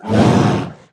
Minecraft Version Minecraft Version snapshot Latest Release | Latest Snapshot snapshot / assets / minecraft / sounds / mob / polarbear / warning3.ogg Compare With Compare With Latest Release | Latest Snapshot